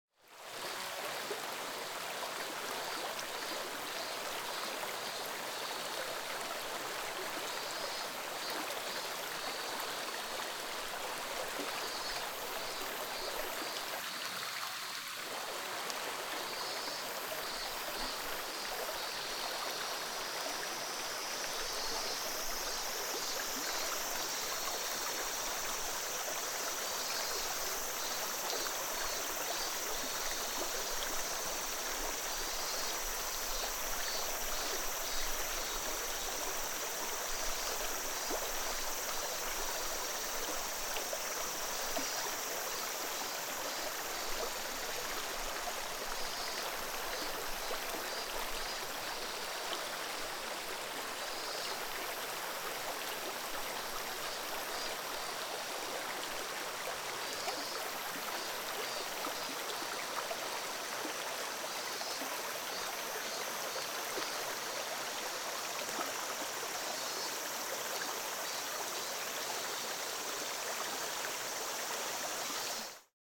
小川のせせらぎ01 - 音アリー
river_stream_01.mp3